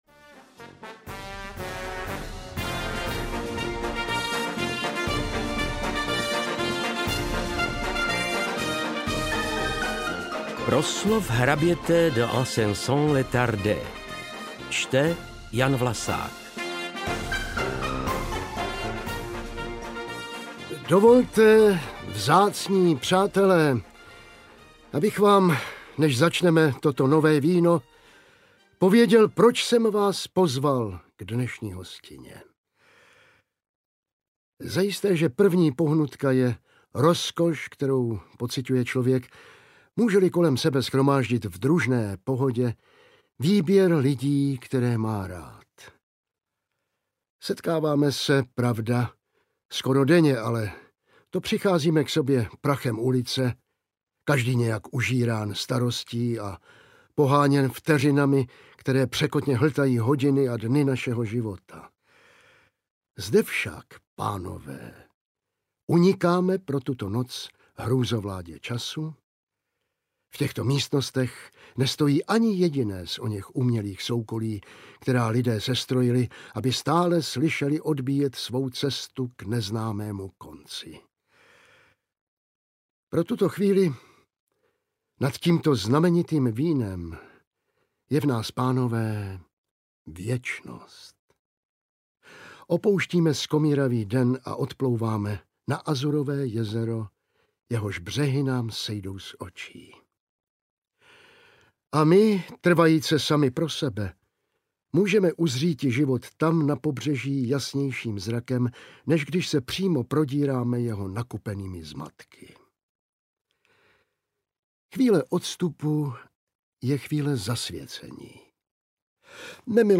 Lidé z maringotek audiokniha
Ukázka z knihy
lide-z-maringotek-audiokniha